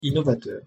Oral (not nasal)
“inn”innovateurinɔvatœʀ
innovateur-pronunciation.mp3